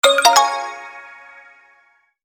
Pop Up Reveal 4 Sound Effect Download | Gfx Sounds
Pop-up-reveal-4.mp3